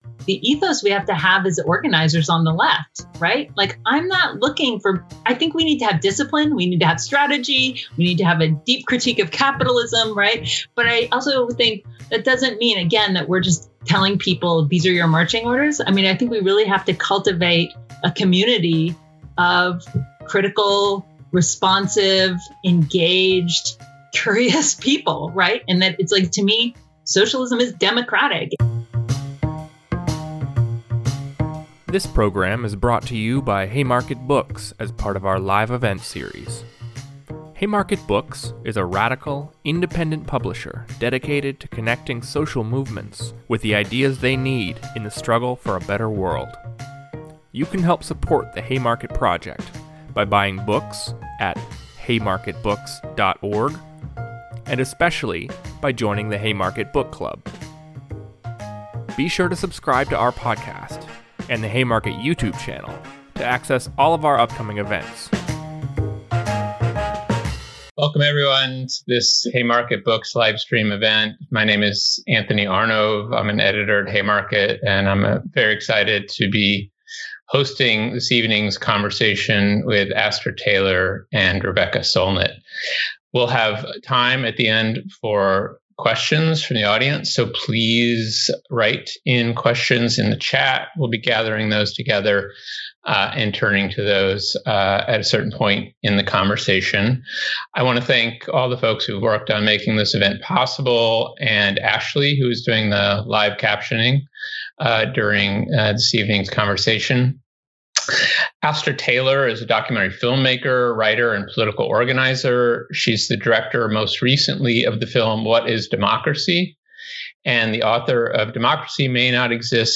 Join acclaimed writers and activists Astra Taylor and Rebecca Solnit as they tackle some of the most pressing social problems of our day.